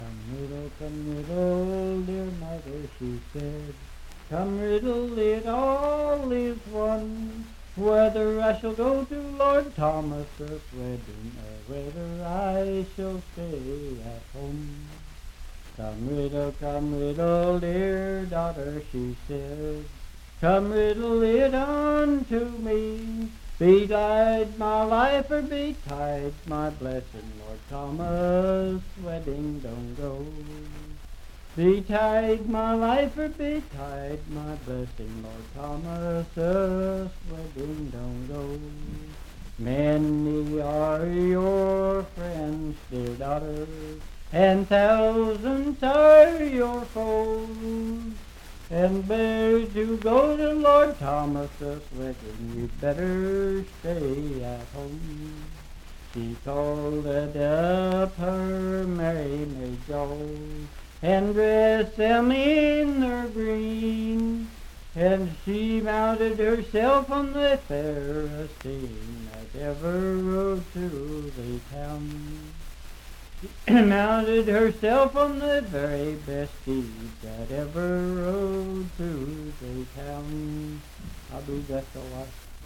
Unaccompanied vocal music
Voice (sung)
Pendleton County (W. Va.)